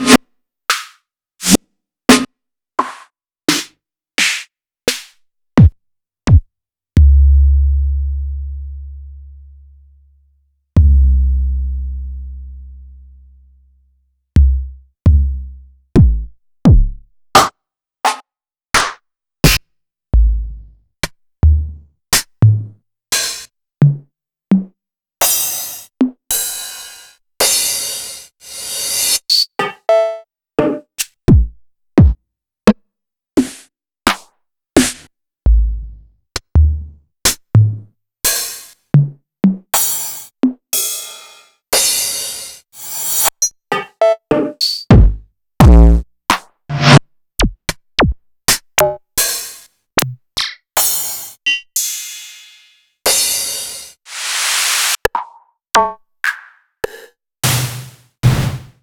Roland_SH-32_Drumset 02.wav